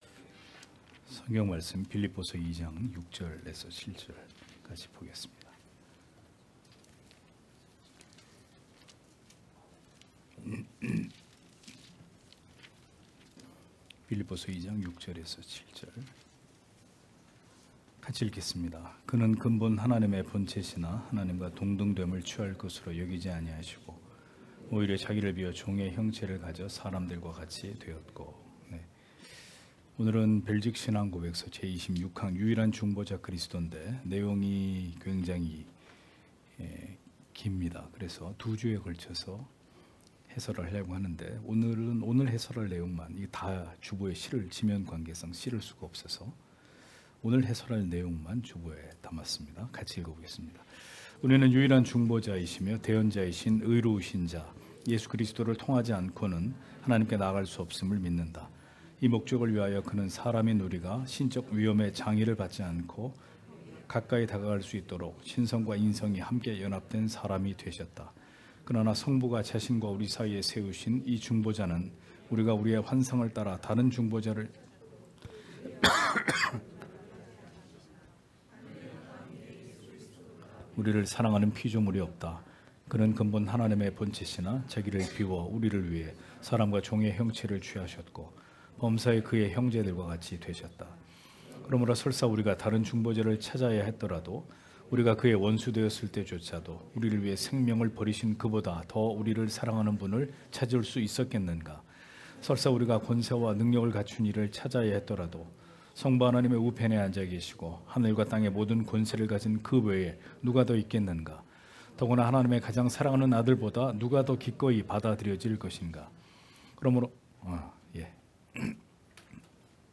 주일오후예배 - [벨직 신앙고백서 해설 29] 제26항 유일한 중보자 그리스도(1) (빌2장6-7절)
* 설교 파일을 다운 받으시려면 아래 설교 제목을 클릭해서 다운 받으시면 됩니다.